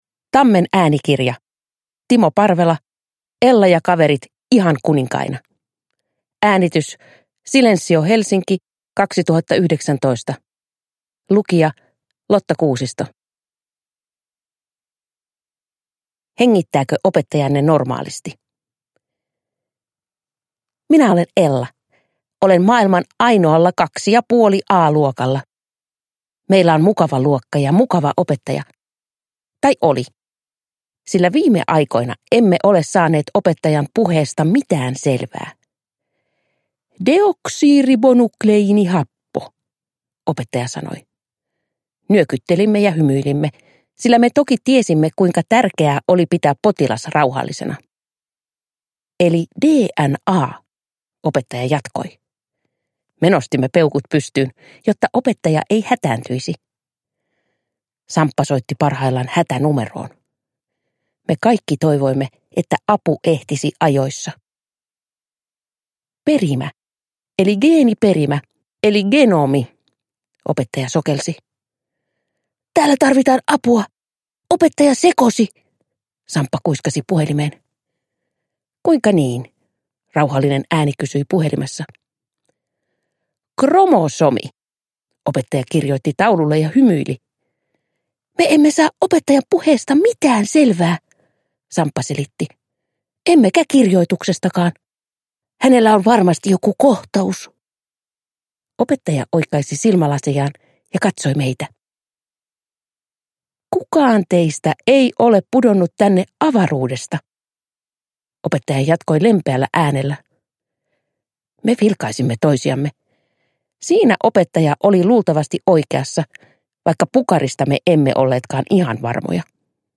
Ella ja kaverit ihan kuninkaina – Ljudbok – Laddas ner